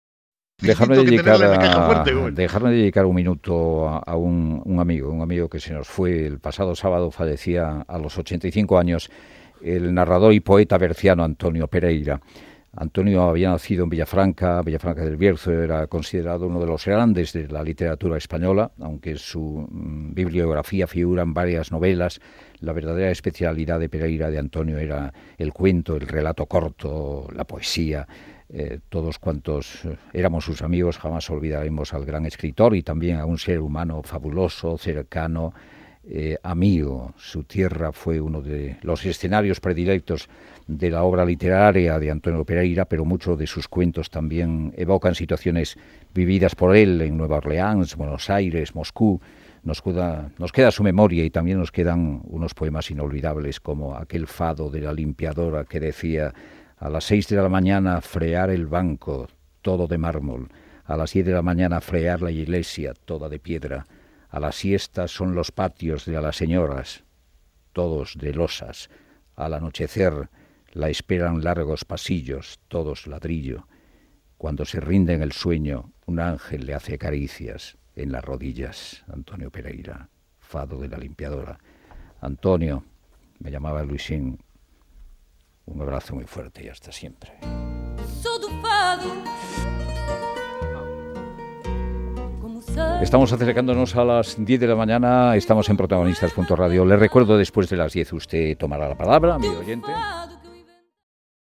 El periodista Luis del Olmo en su programa «Protagonistas» dedica un pequeño homenaje a Antonio Pereira. En este emocionado recuerdo lee uno de los poemas del poetas dedicado a Portugal